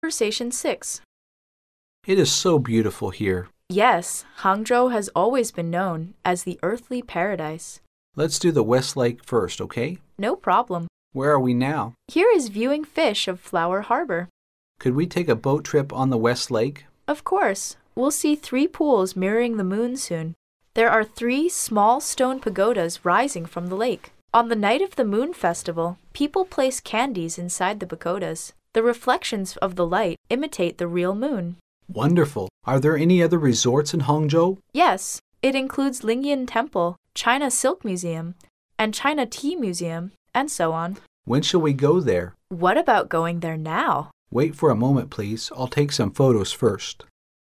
Conversation 6